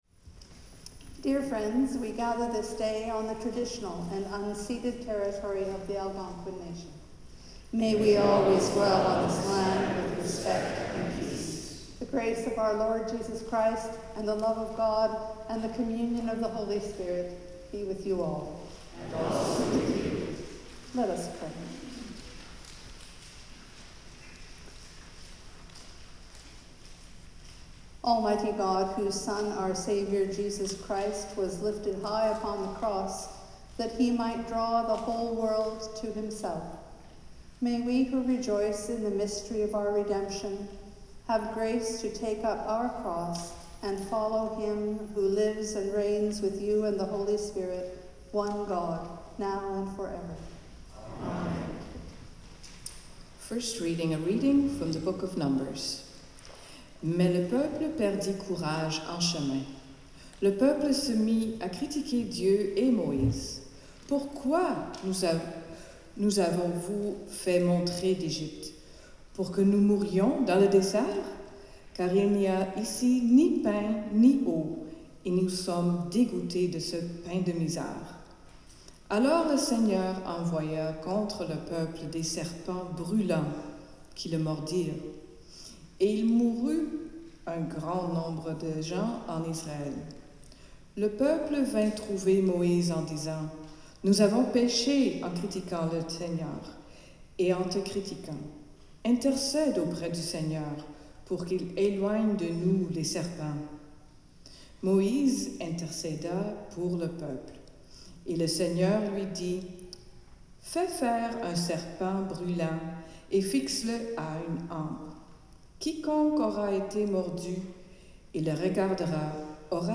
Sermon
Anthem
The Lord’s Prayer (sung)
Hymn 379: Rejoice the Lord is King